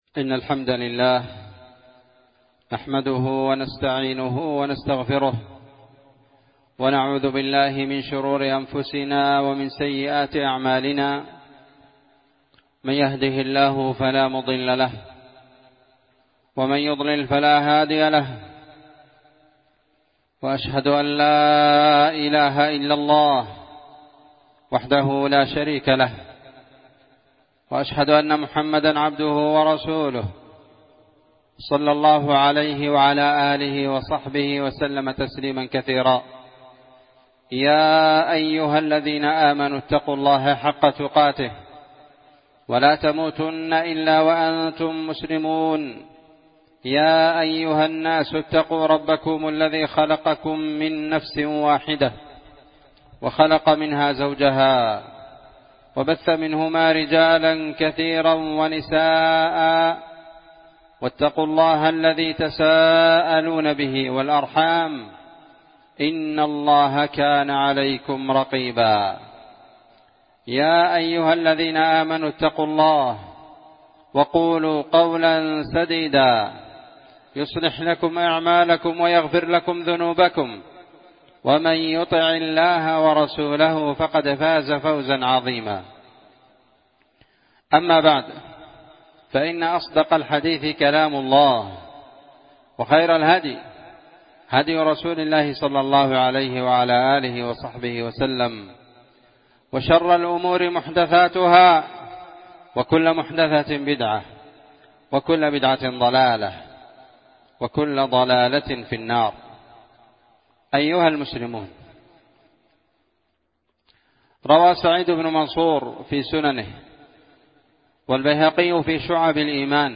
خطبة جمعة
إندونيسيا- جزيرة سولاويسي- مدينة بوني- قرية تيرونج- مسجد الإخلاص